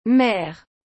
Ela soa como “mér” em português, com um som mais aberto e nasalizado.
Mer.mp3